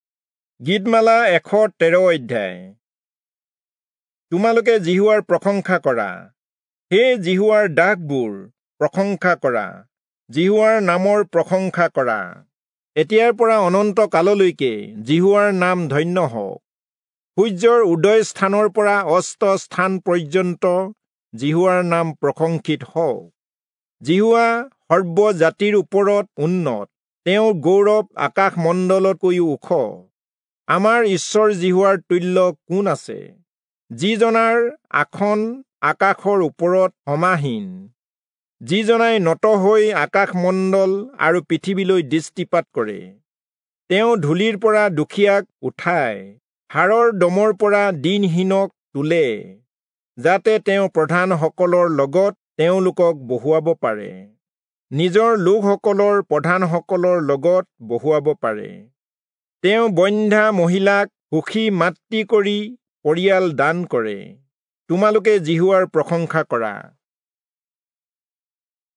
Assamese Audio Bible - Psalms 107 in Pav bible version